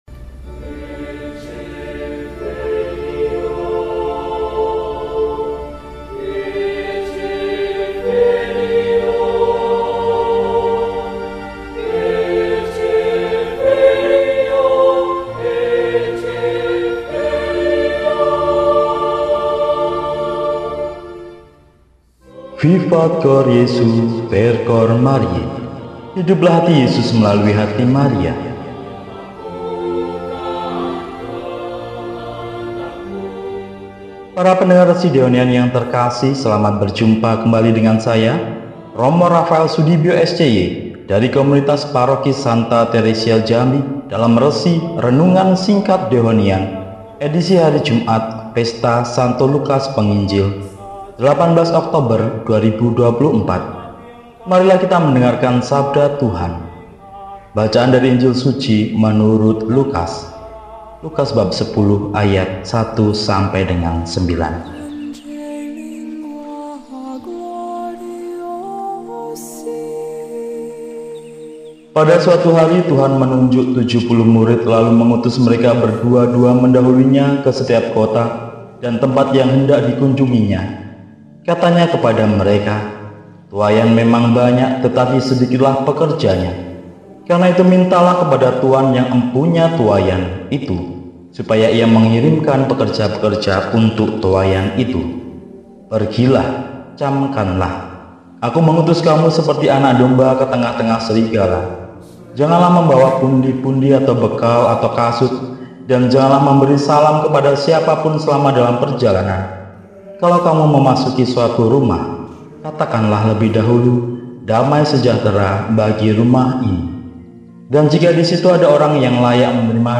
Jumat, 18 Oktober 2024 – Pesta Santo Lukas, Penginjil – RESI (Renungan Singkat) DEHONIAN